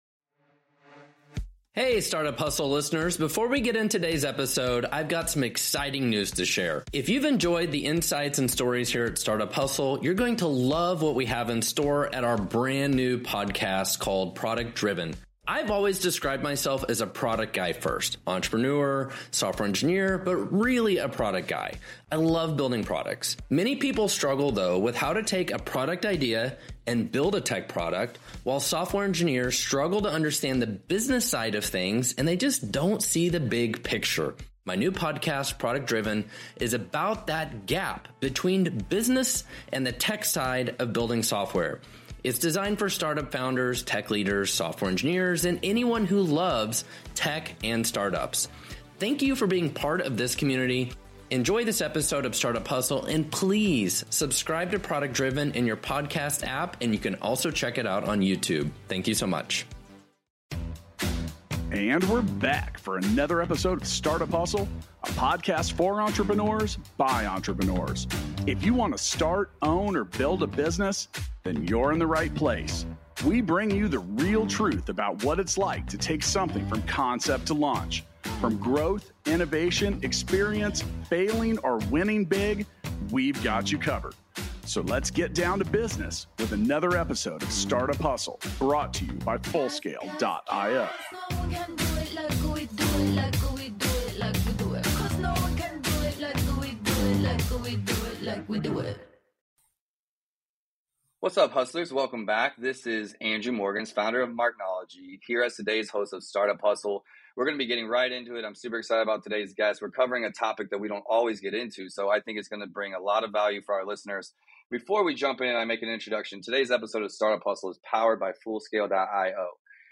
for a great conversation about how to sell online legally. They also share why your business needs a lawyer, why it is essential to be organized, and how to protect your business from trolls.